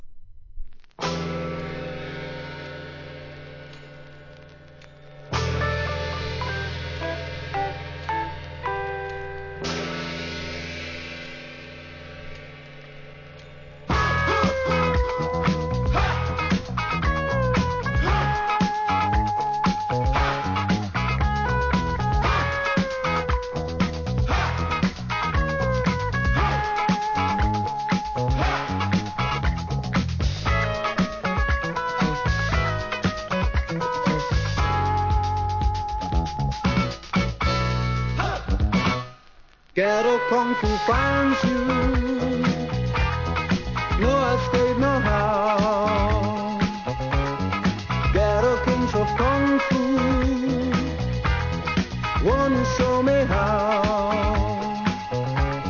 SOUL/FUNK/etc... 店舗 数量 カートに入れる お気に入りに追加 人気DISCOナンバーを4曲！"